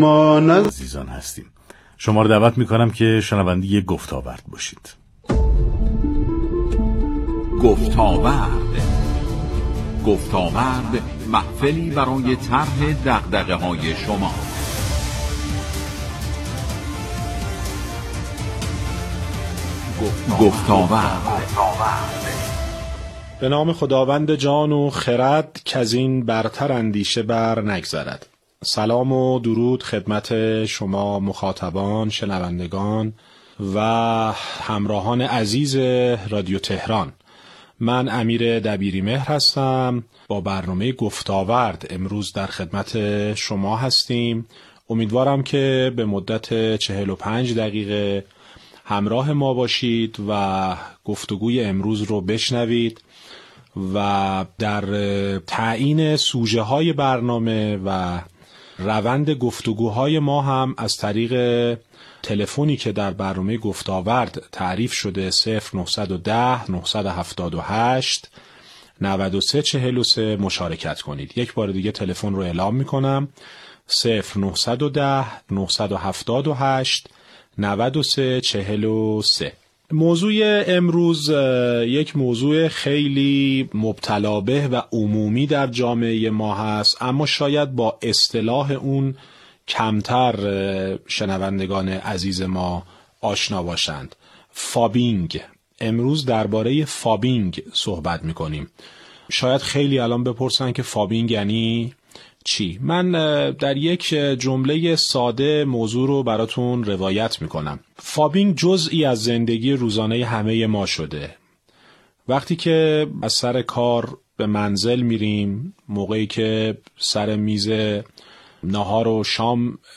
با حضور در برنامه گفتاورد رادیو تهران